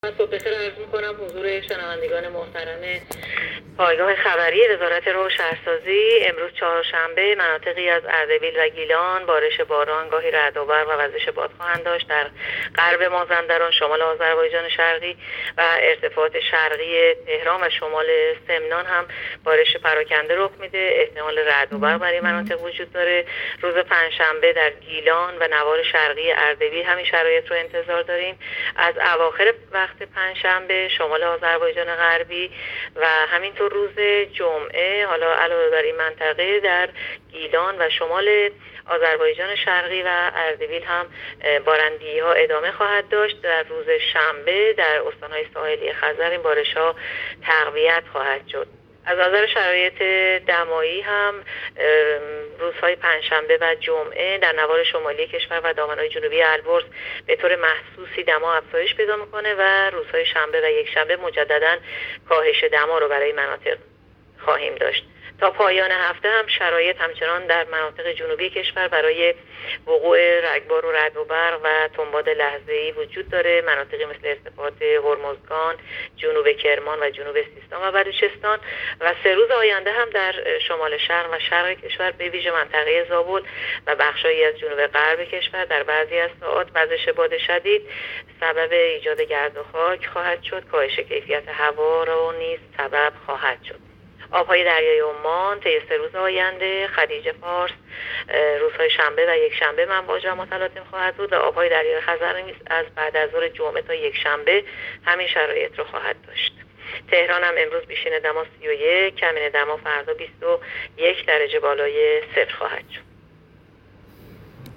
گزارش رادیو اینترنتی پایگاه‌ خبری از آخرین وضعیت آب‌وهوای ۲۶ شهریور؛